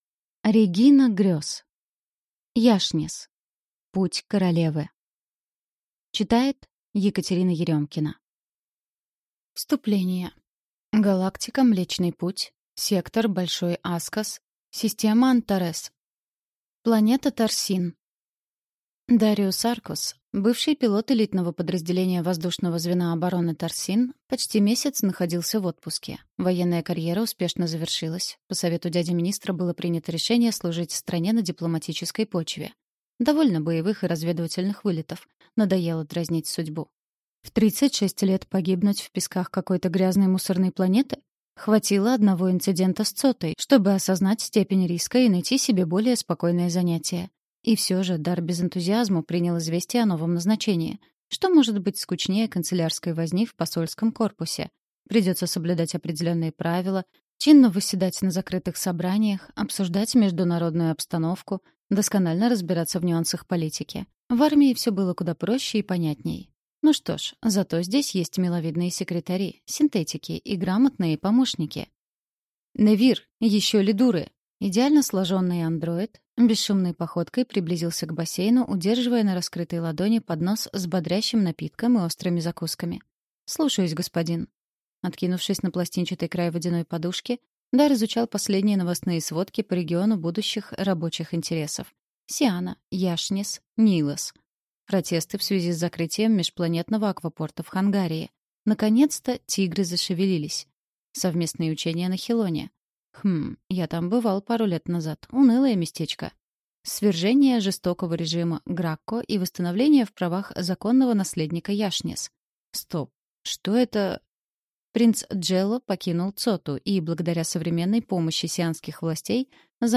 Аудиокнига Яшнисс. Путь королевы | Библиотека аудиокниг